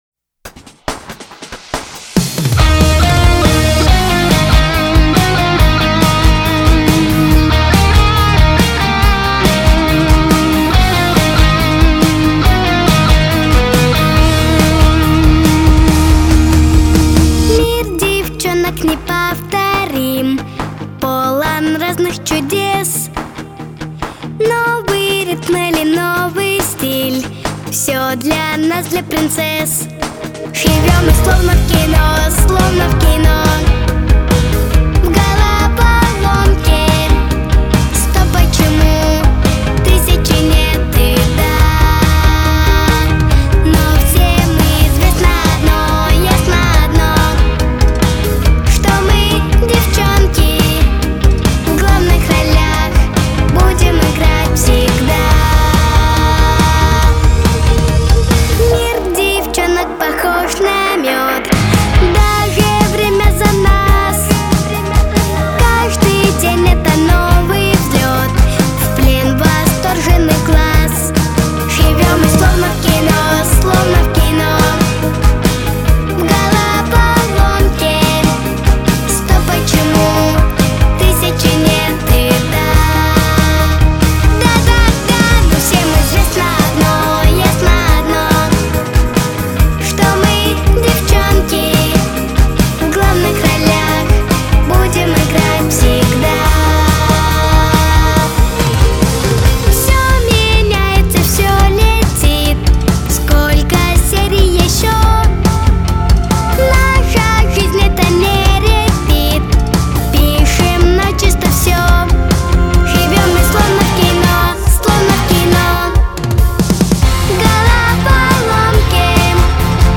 Характер песни: весёлый.
Темп песни: быстрый.
Диапазон: До♭ первой октавы - Фа второй октавы.